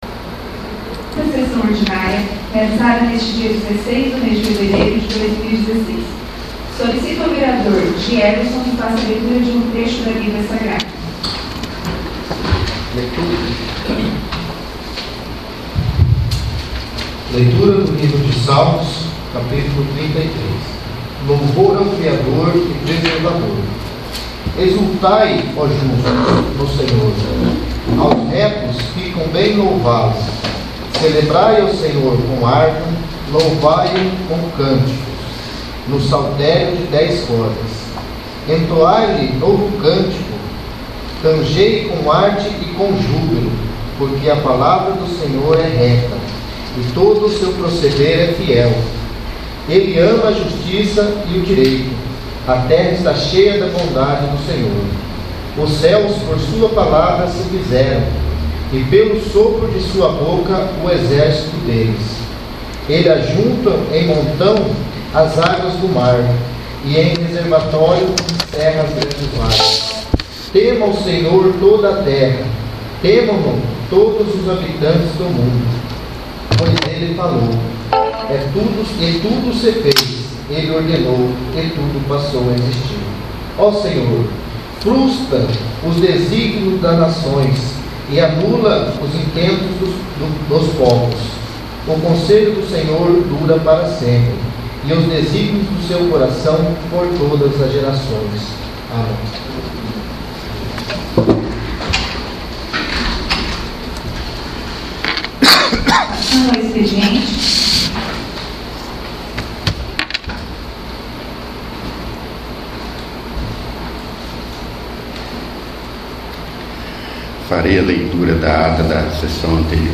2º. Sessão Ordinária